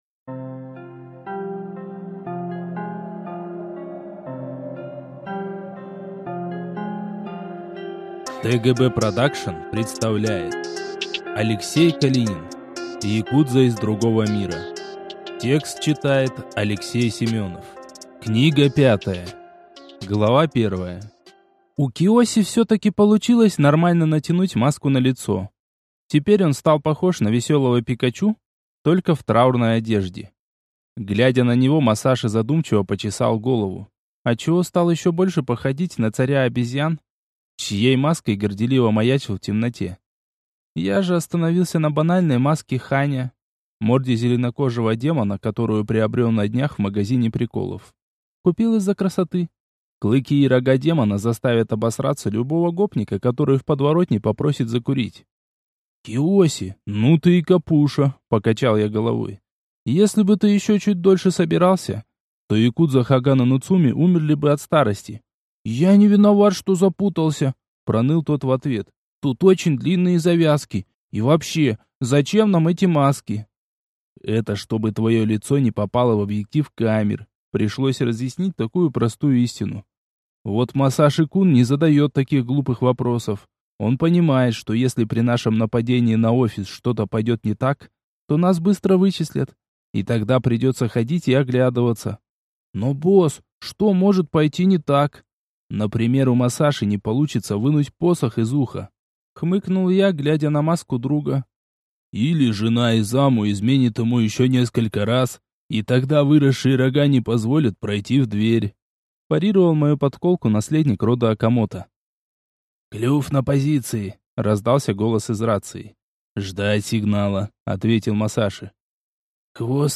Аудиокнига Якудза из другого мира 5 | Библиотека аудиокниг
Прослушать и бесплатно скачать фрагмент аудиокниги